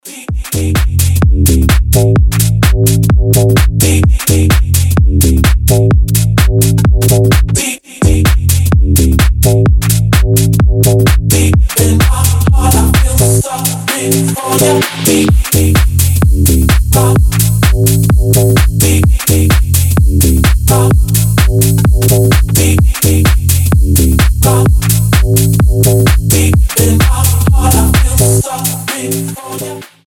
ритмичные
Bass House
стильные
динамичные
Диповый басс хаус